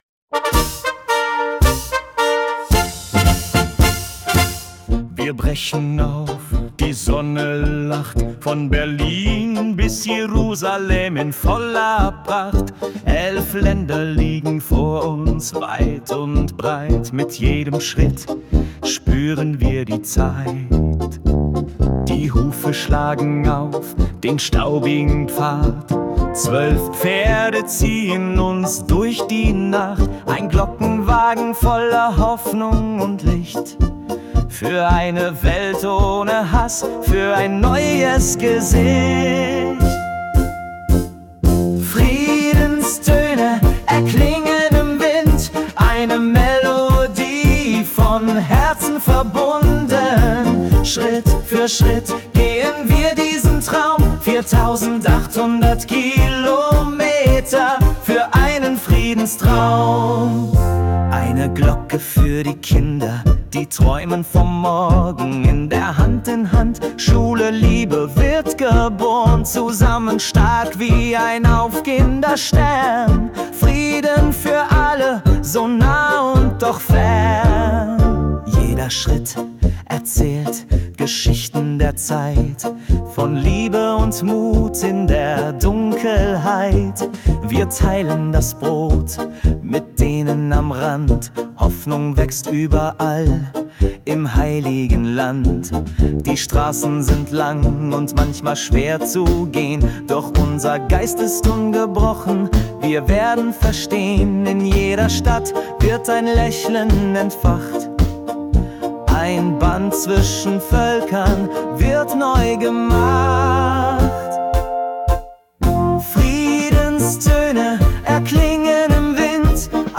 >>> das gesungene Lied … und der Download.